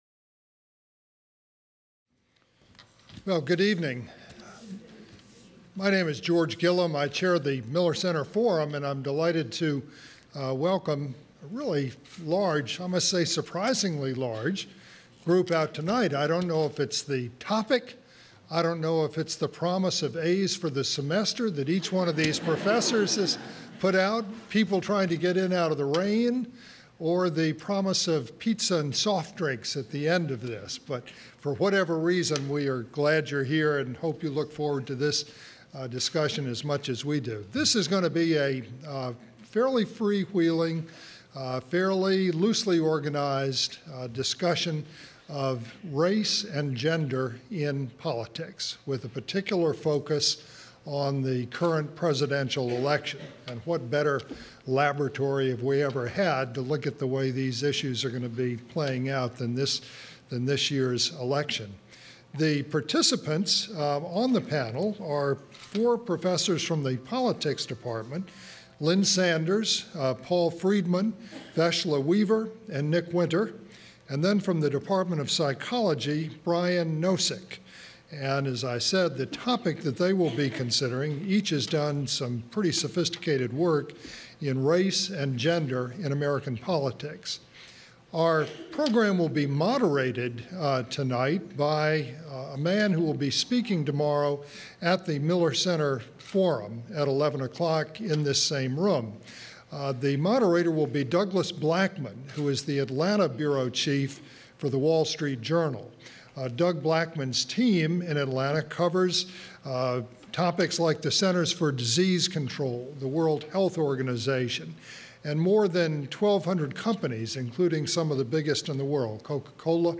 Race and Gender in American Politics Roundtable Event | Miller Center